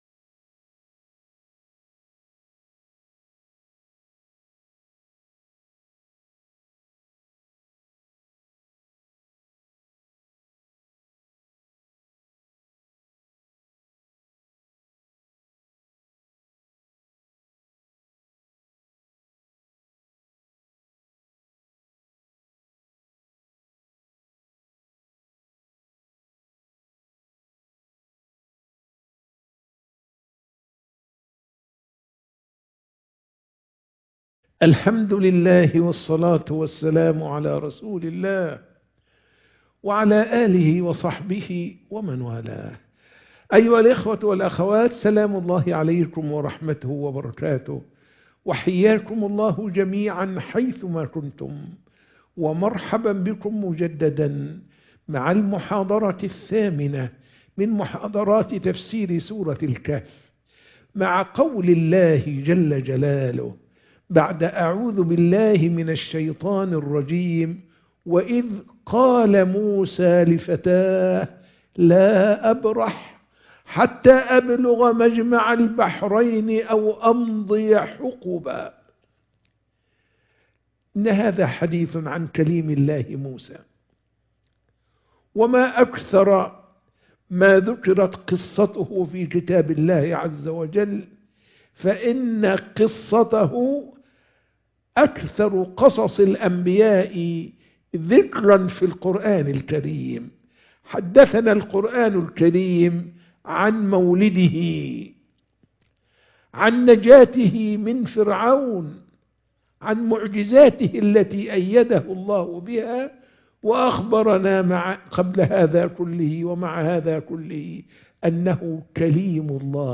سورة الكهف - المحاضرة 8